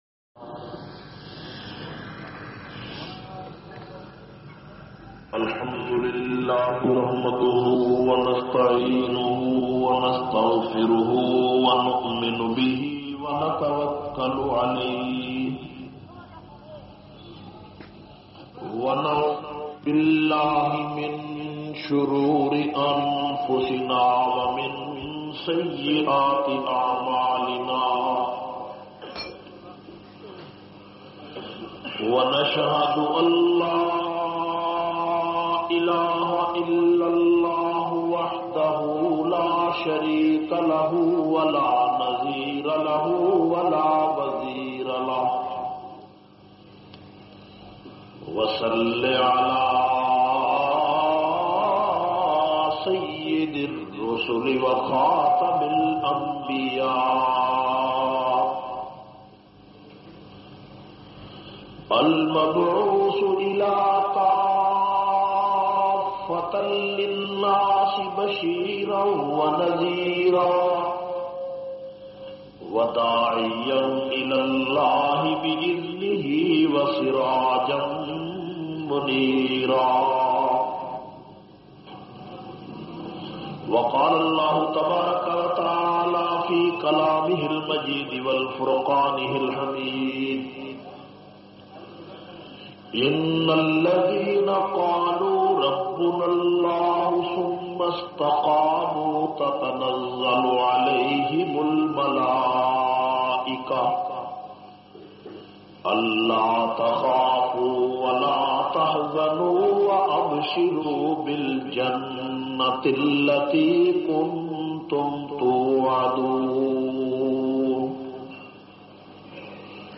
212- Tareekh e Hind Aulia e Ummat conference Peshawar.mp3